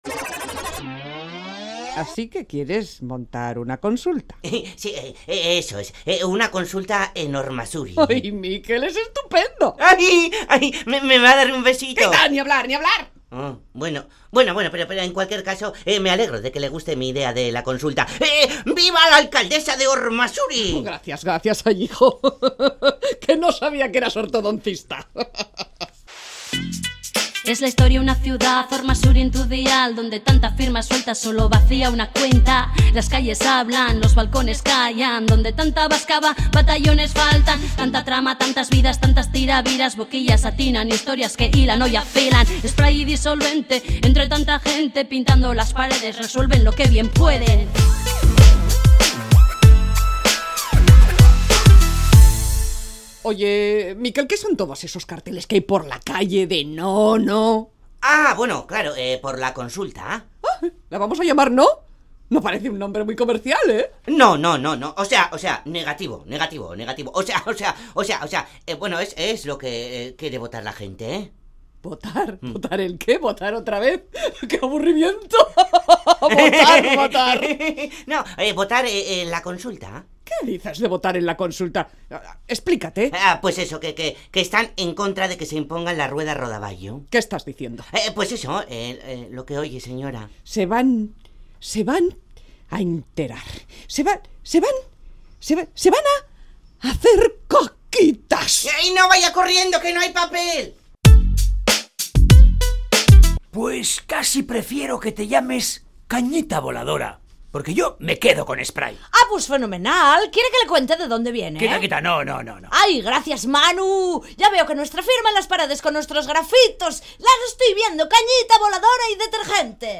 Séptima entrega de la Radio-Ficción “Spray & Disolvente”